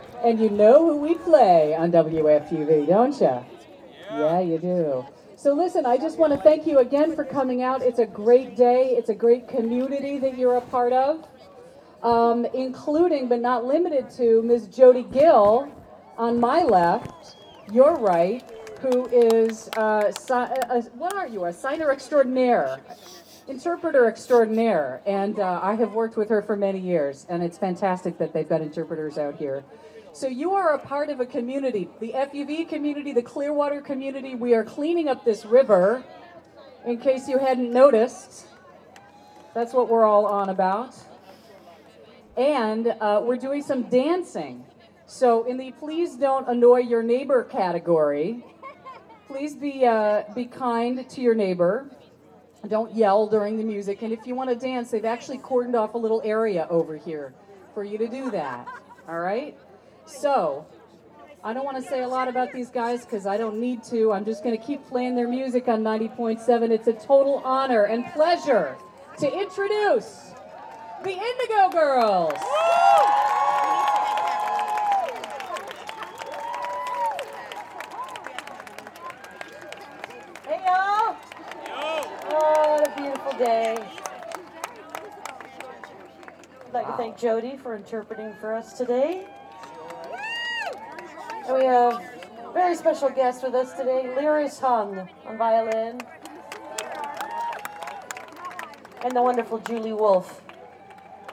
lifeblood: bootlegs: 2011-06-19: croton point park (clearwater festival) - hudson, new york